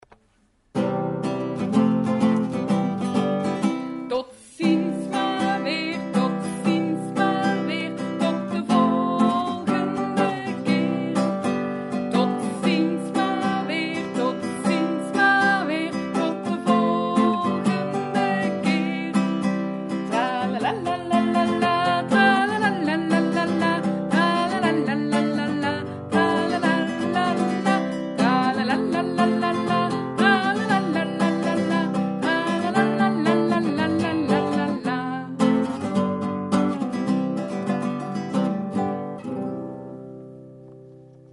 openings- en slotliedjes van de muziekles